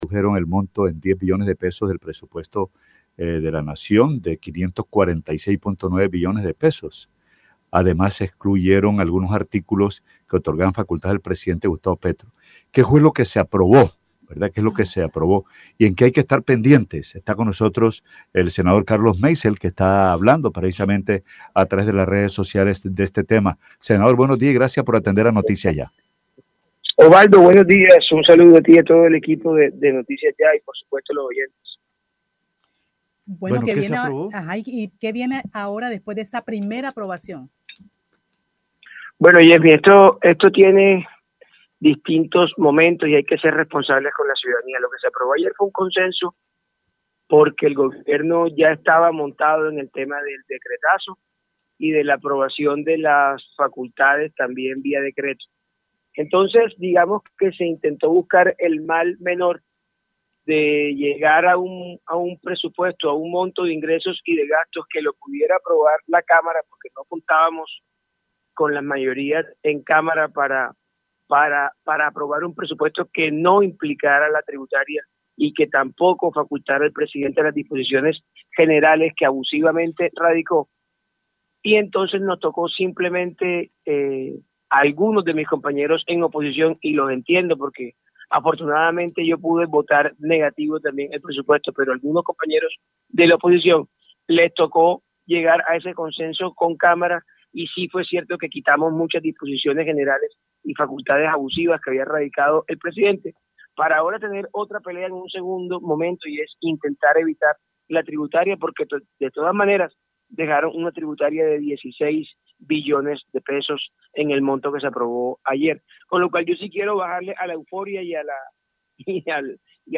El senador Carlos Meisel, en entrevista con Noticiaya, explicó que la reducción de $10 billones fue un «mal menor» que buscó evitar un «decretazo» del gobierno.